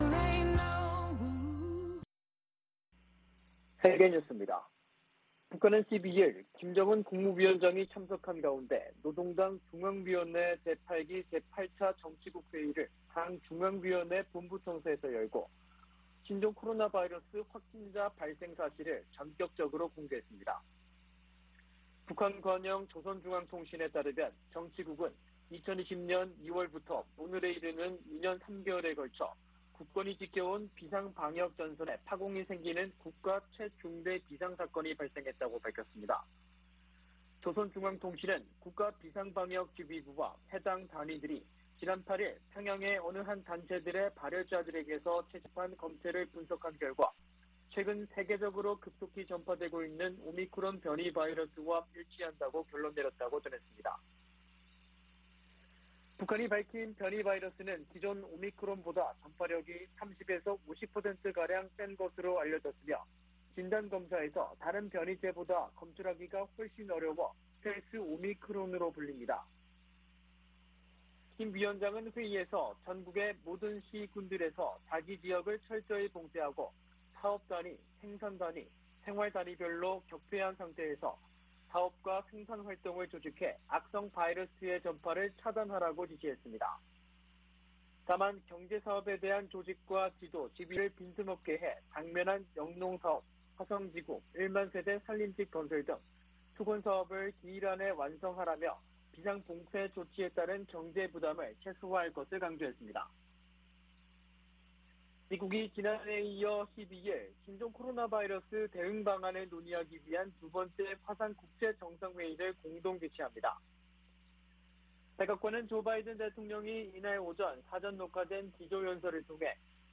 VOA 한국어 아침 뉴스 프로그램 '워싱턴 뉴스 광장' 2022년 5월 13일 방송입니다. 북한이 또다시 탄도미사일을 발사했습니다.